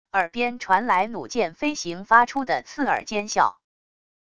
耳边传来弩箭飞行发出的刺耳尖啸wav音频